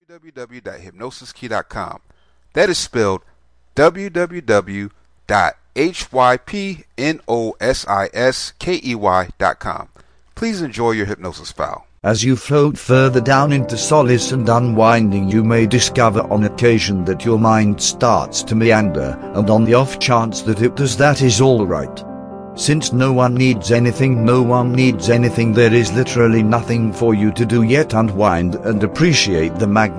Perfectionism Self Hypnosis Mp3